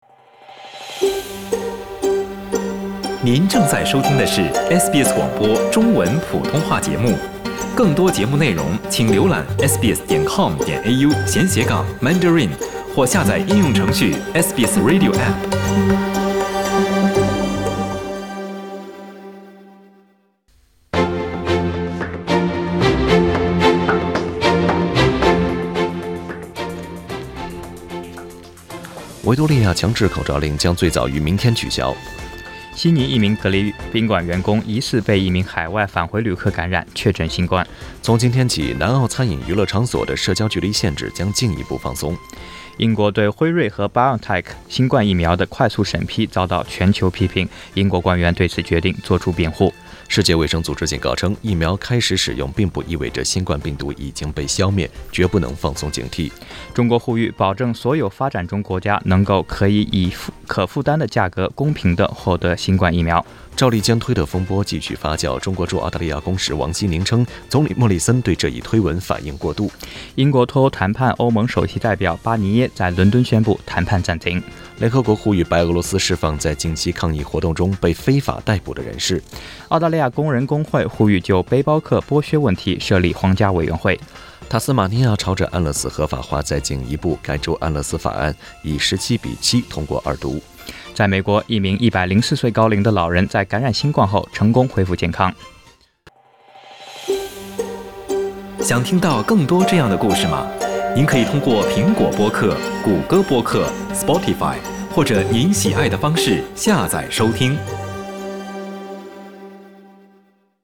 SBS早新聞（12月5日）